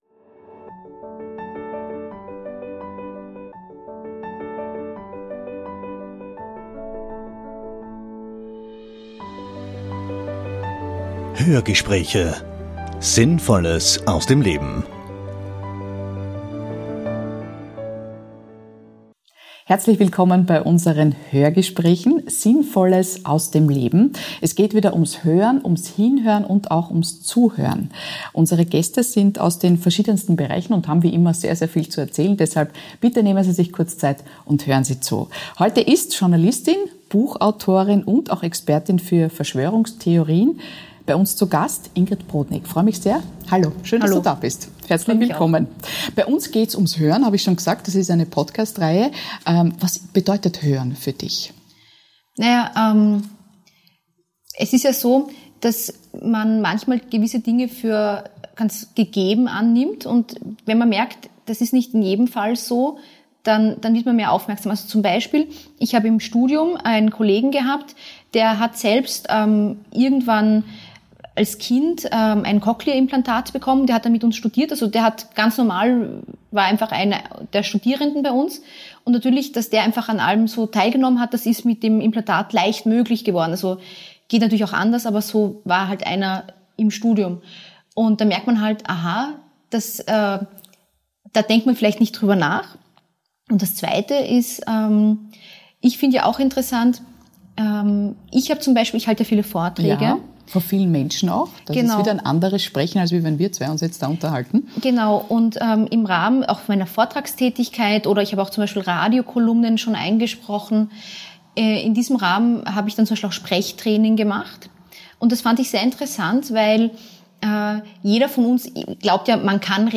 Das spannende Interview mit der mehrfach ausgezeichneten digitalen Expertin Ingrid Brodnig.